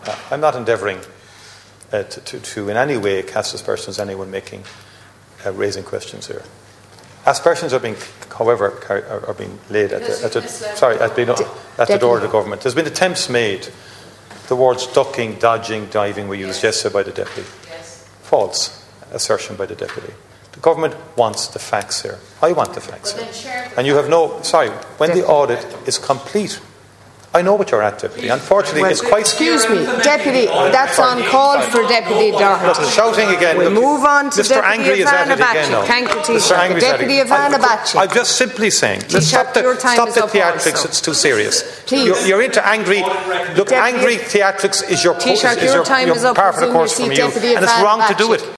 He clashed with Sinn Fein’s Pearse Doherty and Mary Lou McDonald, claiming they were alleging a government cover up: